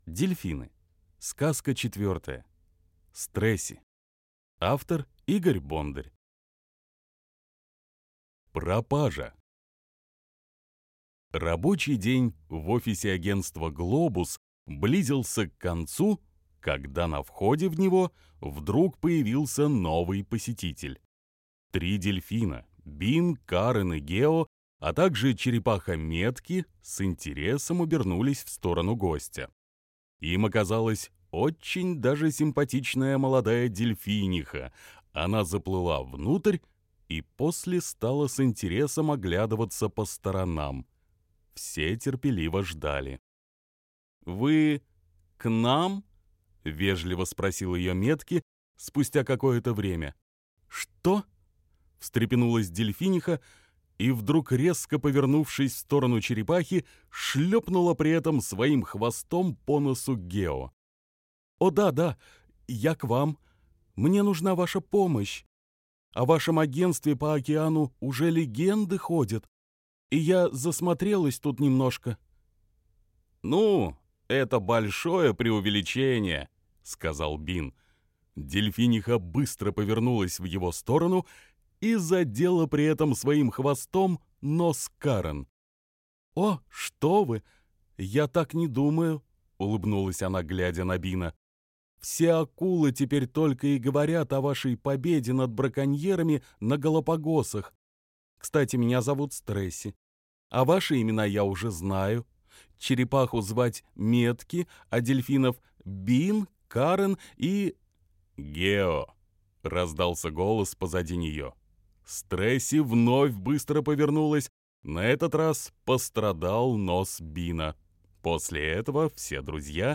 Дельфины. Стрэси - аудиосказка Бондаря - слушать онлайн